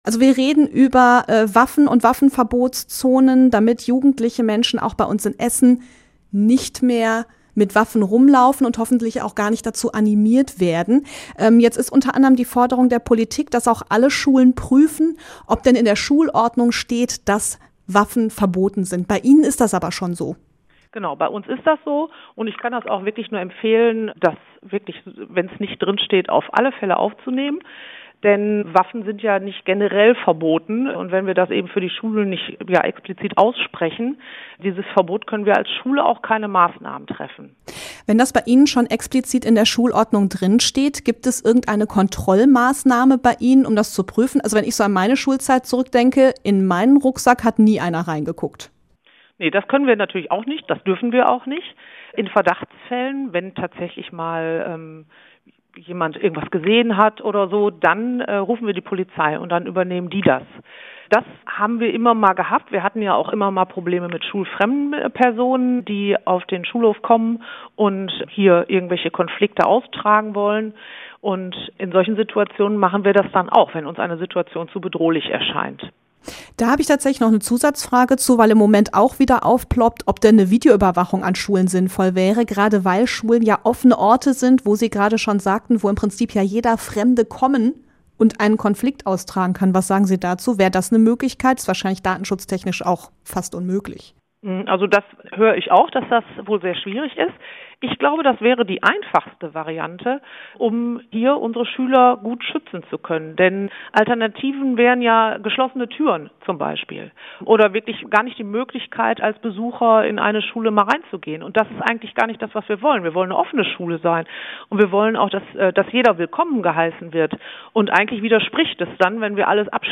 int-waffenverbot-an-schulen-fuer-online.mp3